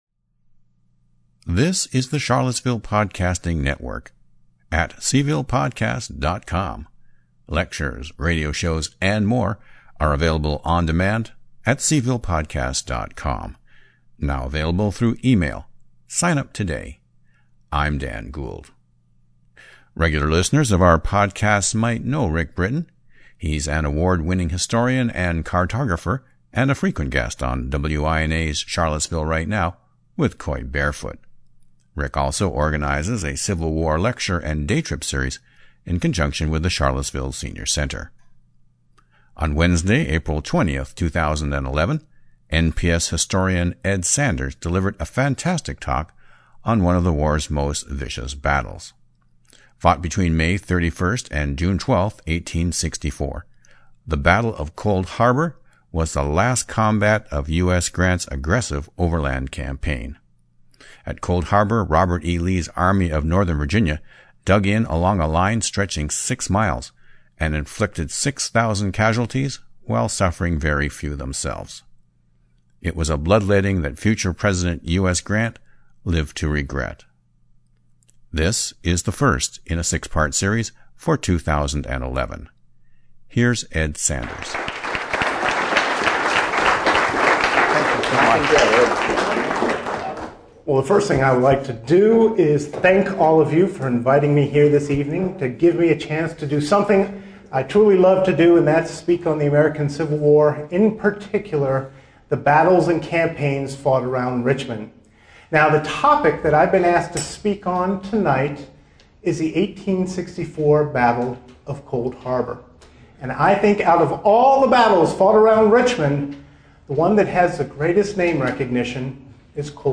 History buffs fill the Charlottesville Senior Center on a rainy Wednesday evening to enjoy the final lecture in this series for 2011.